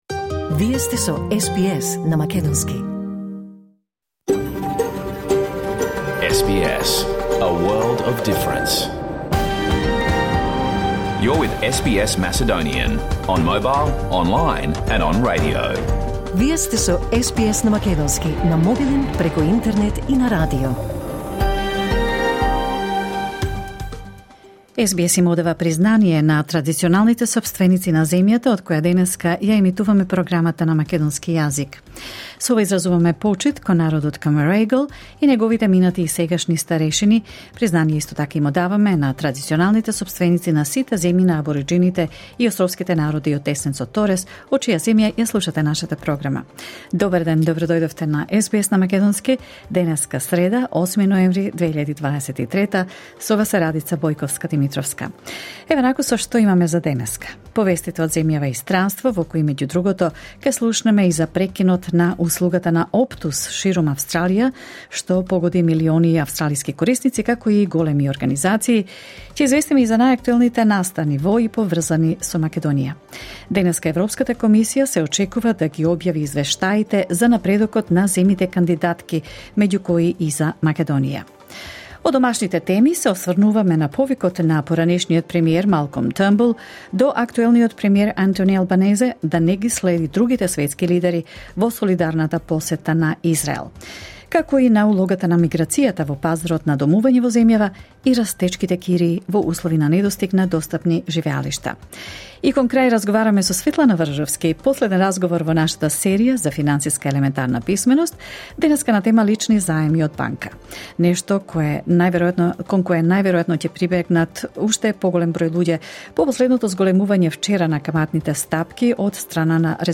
SBS Macedonian Program Live On Air 8 November 2023